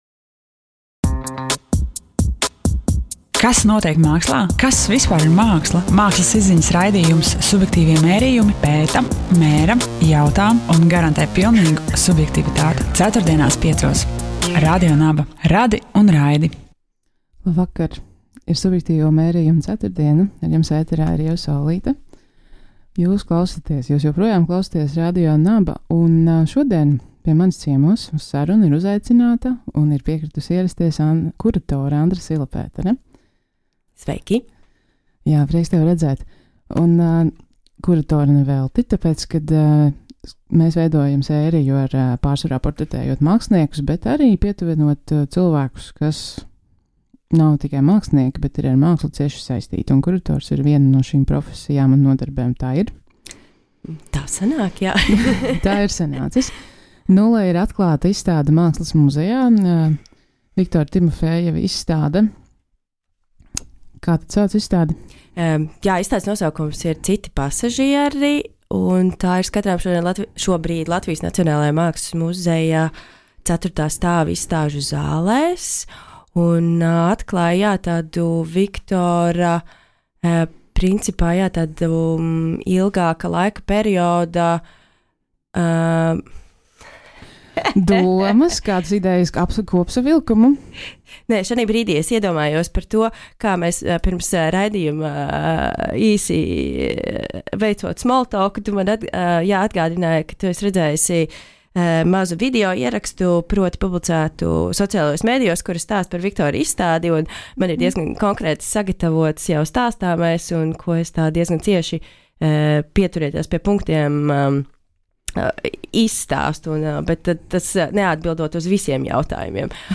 “Subjektīvo mērījumu” mērķis ir vēstīt par mākslu, veidojot tematiskas diskusijas un aktuālas intervijas, lai ar šī raidījuma palīdzību varētu informēt un ieinteresēt mākslas notikumos.
Raidījuma ietvaros uz sarunām un diskusijām tiek aicināti mākslas pazinēji – kuratori, mākslas zinātnieki, kritiķi, mākslinieki, radošajās mākslas institūcijās strādājošie, kā arī citi mākslas interesenti, kas palīdz klausītājam orientēties mūsdienu mākslas notikumos un koncepciju izpratnē.